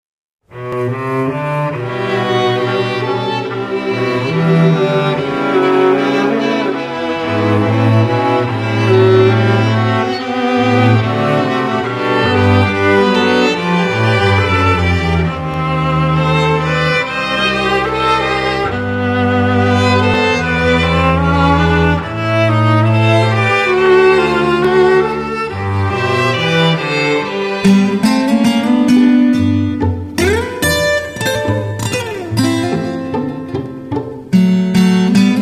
viola caipira